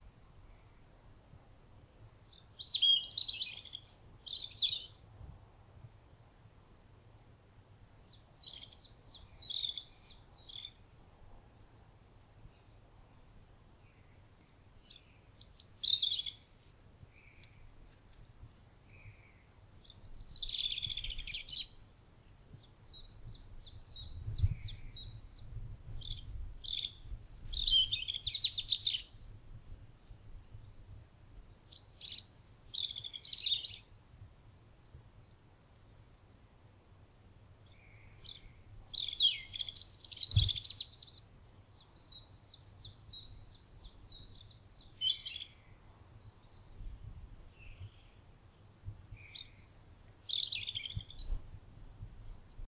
European robin
European robin song
The robin produces a fluting, warbling